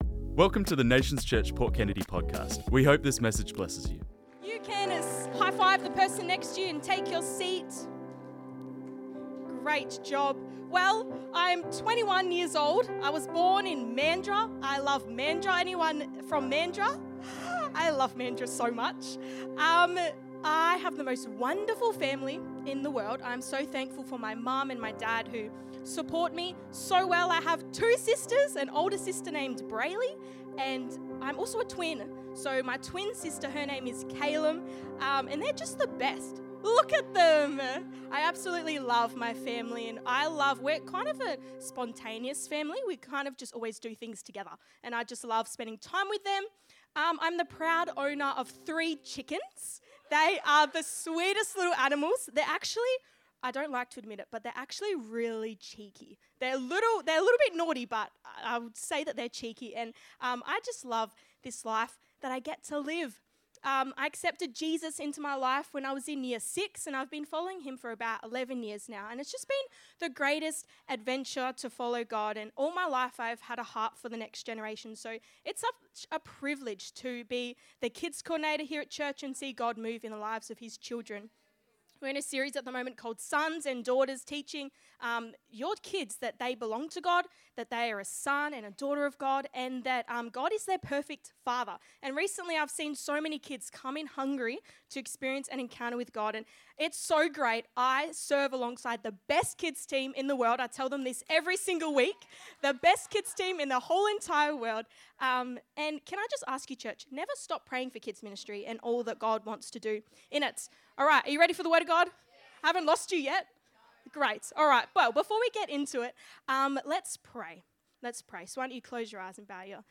This message was preached on Sunday 19th January 2025